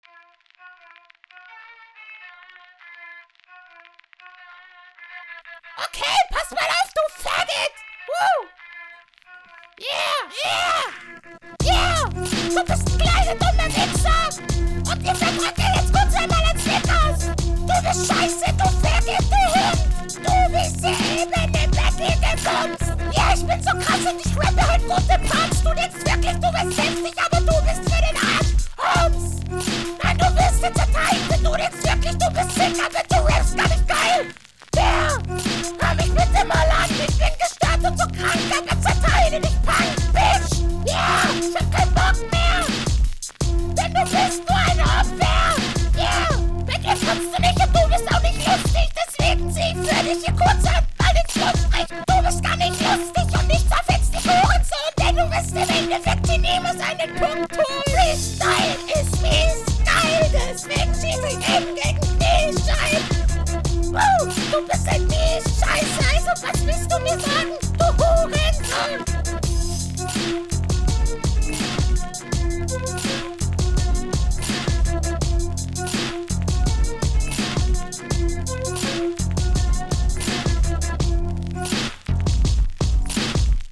Ist halt irgendwo ,,normaler" gerappt, aber mit einer …